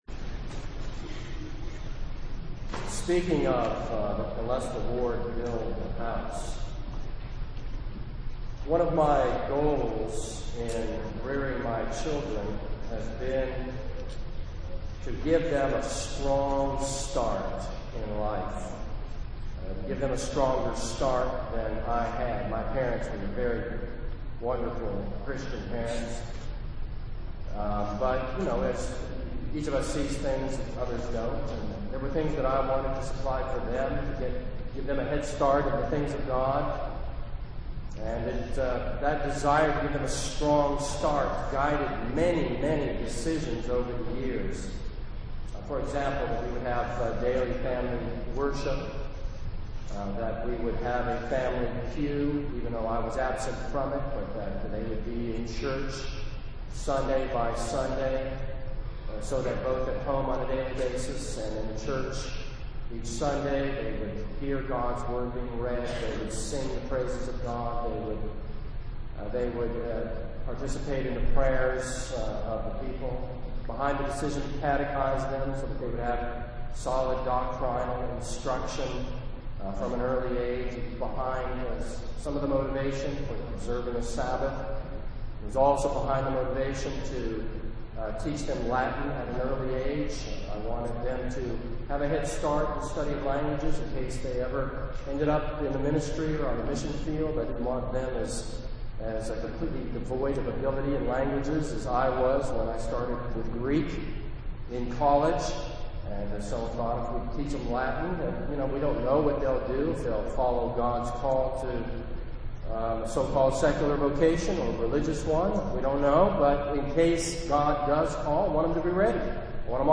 This is a sermon on 2 Kings 12.